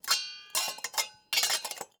Babushka / audio / sfx / Kitchen / SFX_Cooking_Pot_03.wav
SFX_Cooking_Pot_03.wav